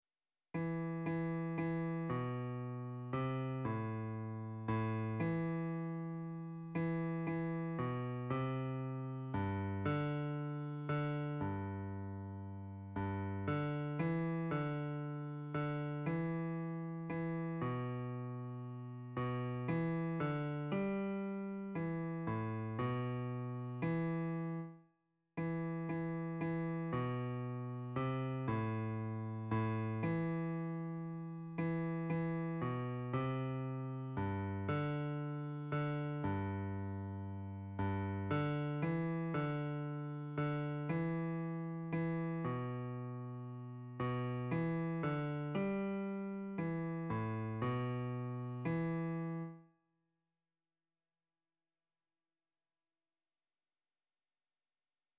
Répétition SATB par voix
Basses